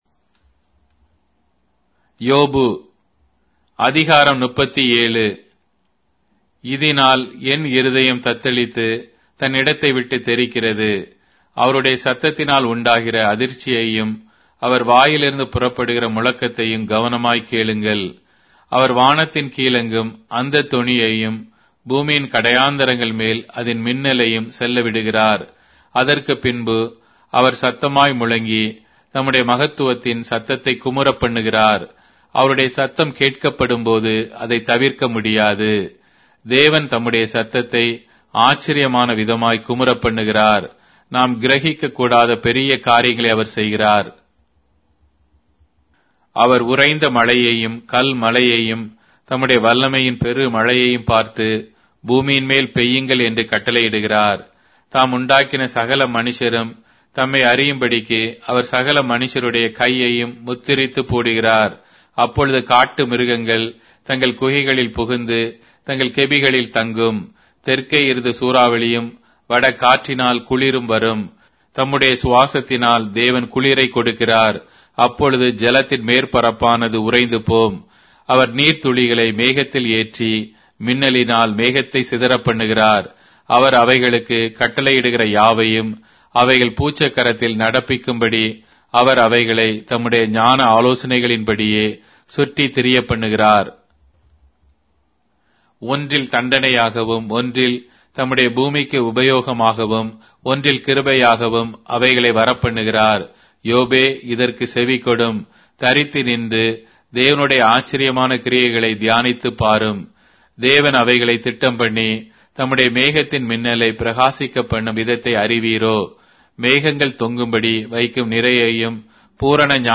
Tamil Audio Bible - Job 12 in Ylt bible version